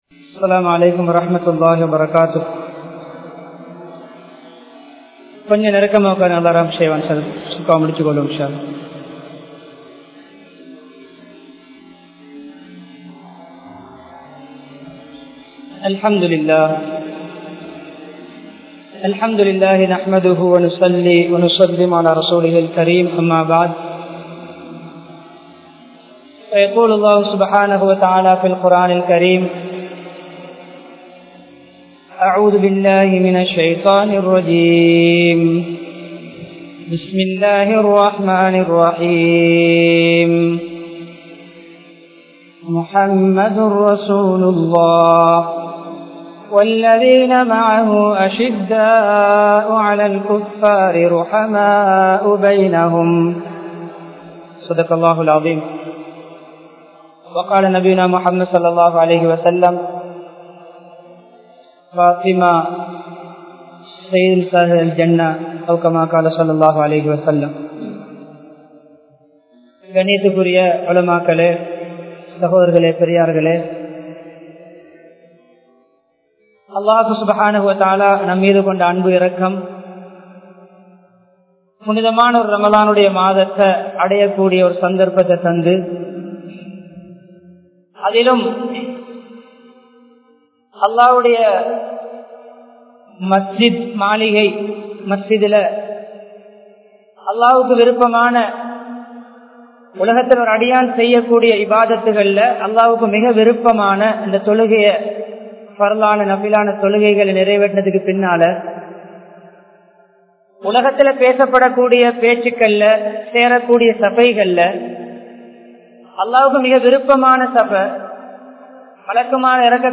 Fathima(Rali)Avarhalin Mahimai (பாத்திமா(ரழி) அவர்களின் மகிமை) | Audio Bayans | All Ceylon Muslim Youth Community | Addalaichenai
Colombo 02, Wekanda Jumuah Masjidh